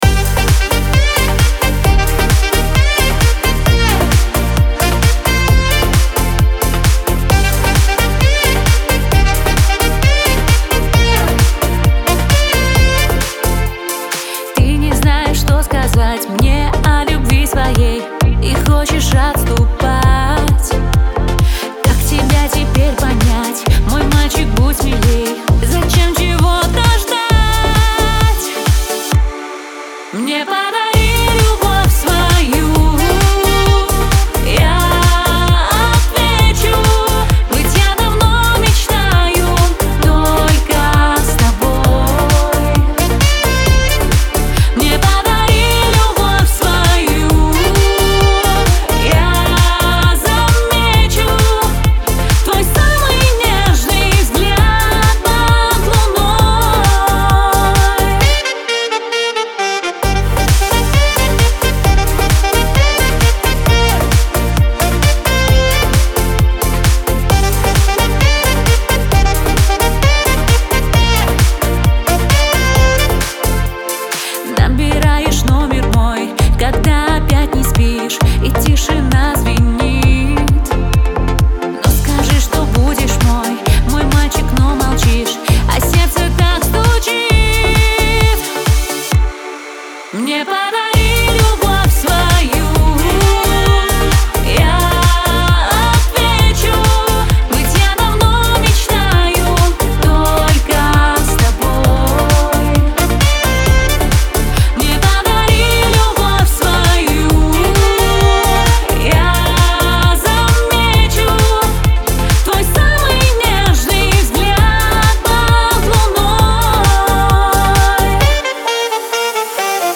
pop
диско